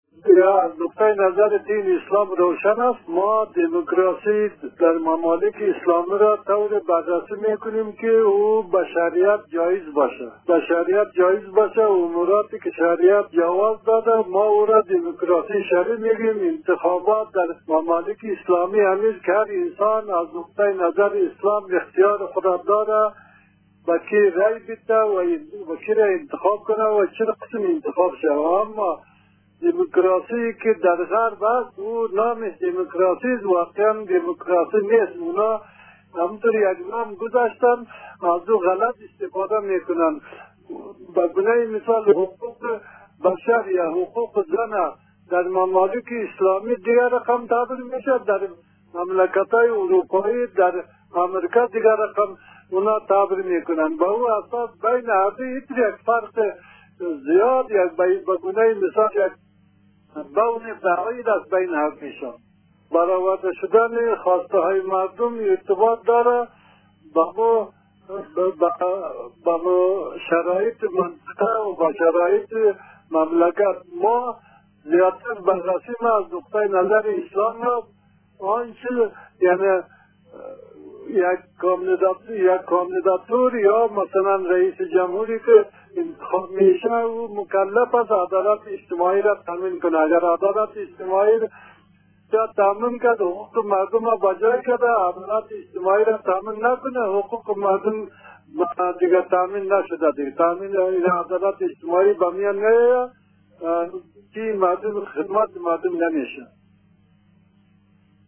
یک کارشناس مسایل مذهبی و سیاسی افغان در گفتگو با خبرنگار رادیو دری در مورد موضوع انتخابات در اسلام گفت،در دین مبین اسلام هر انسانی آزاد آفریده شده و...